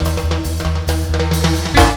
FILLTIMB03-L.wav